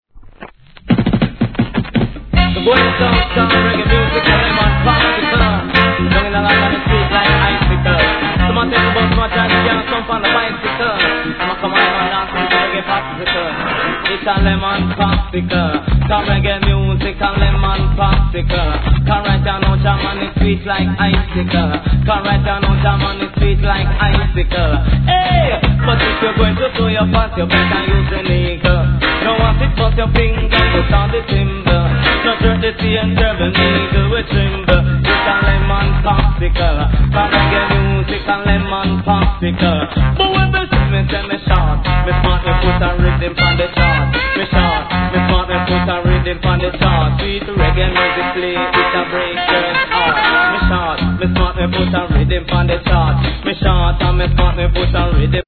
プレス時の起因と思われるノイズあります